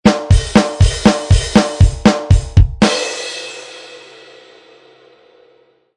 Descarga de Sonidos mp3 Gratis: bateria 8.
bateria-.mp3